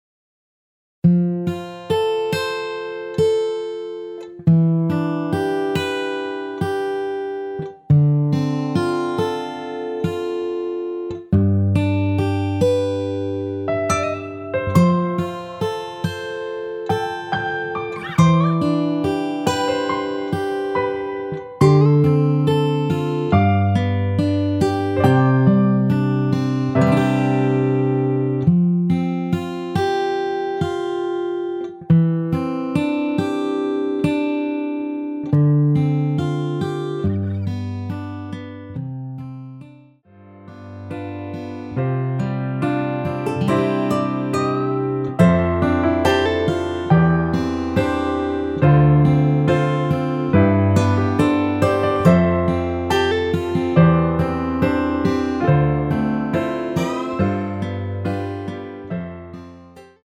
여자키에서 (-1) 내린 MR 이며 여성분이 부르실수 있는키의 MR 입니다.(미리듣기 참조)
앞부분30초, 뒷부분30초씩 편집해서 올려 드리고 있습니다.
축가 MR